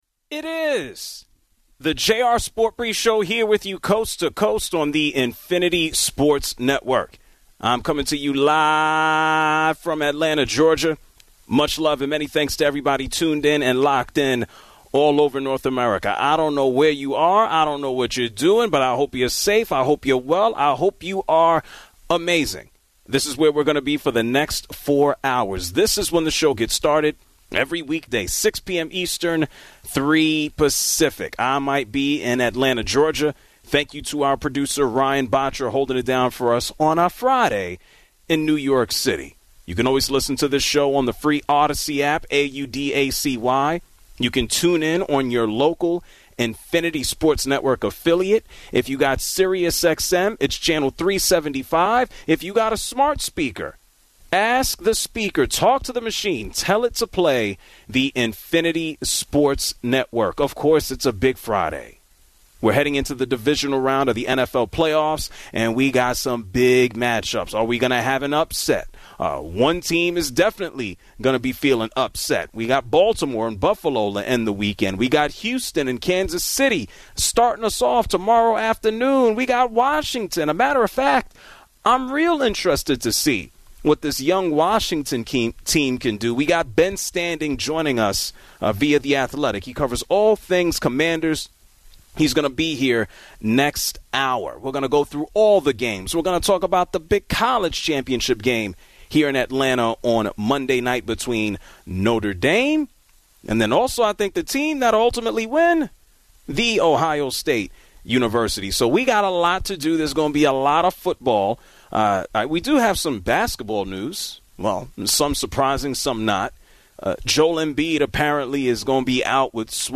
takes your calls on the Ravens @ Bills game.